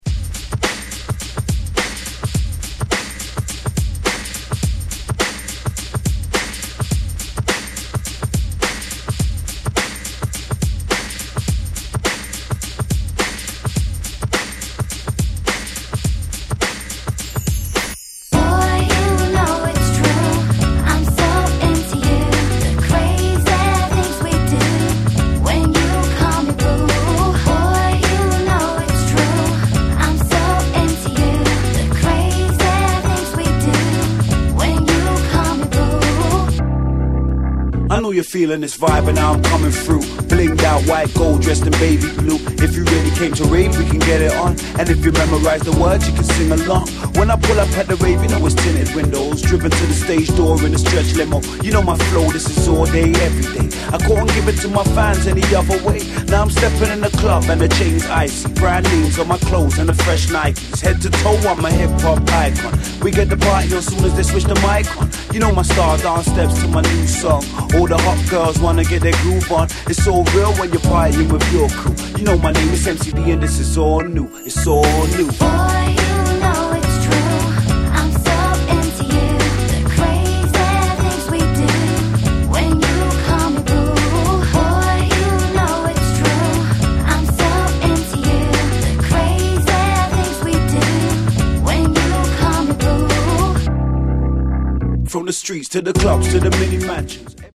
しっかりとした歌Rap物に仕上がっております！